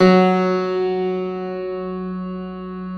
53c-pno08-F1.wav